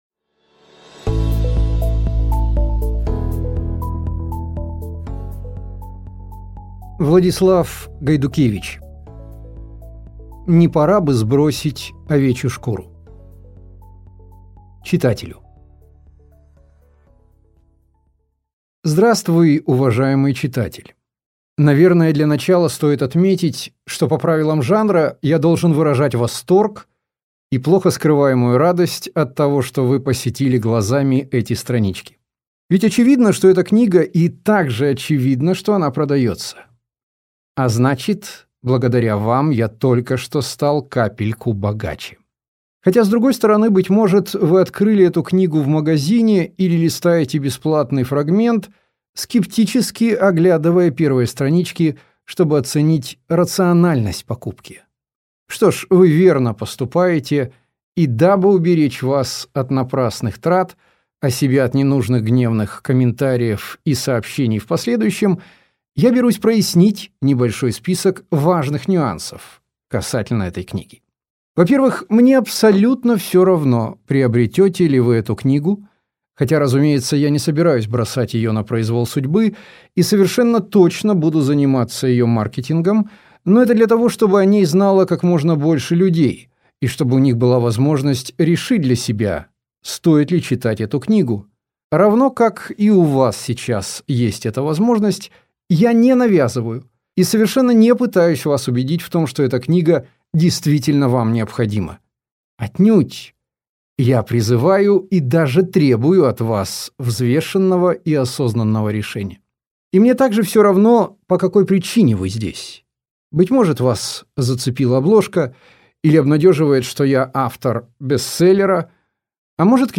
Аудиокнига Расширить сознание легально. Не пора ли сбросить овечью шкуру? | Библиотека аудиокниг